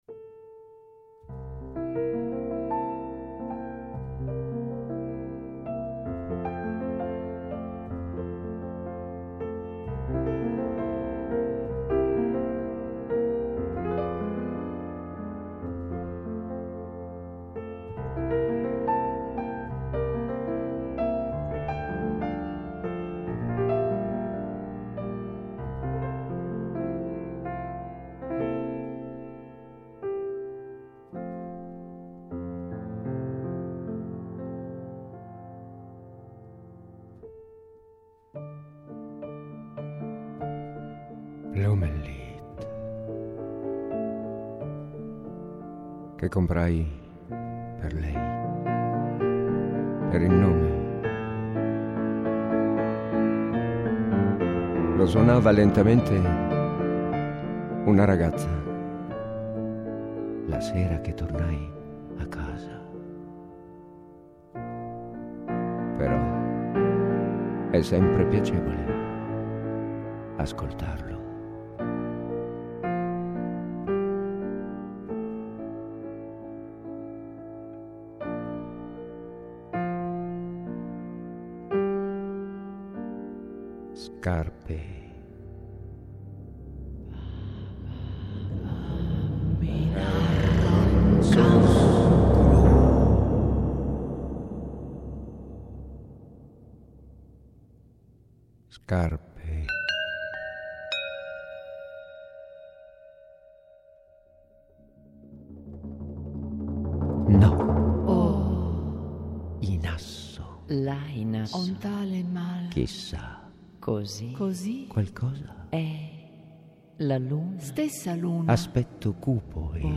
Versione radiofonica tratta dall'opera teatrale